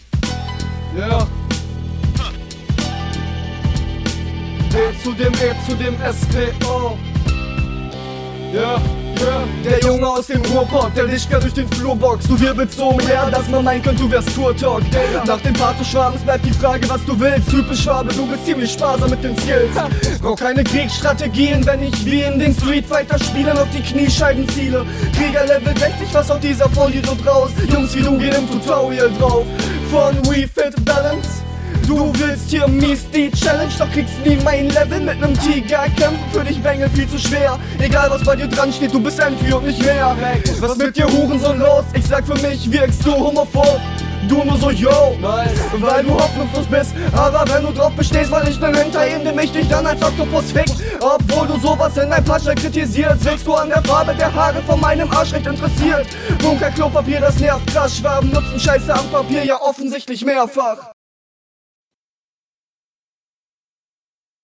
mix wieder weak. hast auch nicht soo präzise gedoubled manchmal, was den hörgenuss nochmal stört.
Hier finde ich deine Soundqualität bisher am besten!
Mische wieder reudig, aber flowtechnisch ziehs du hier alle Register!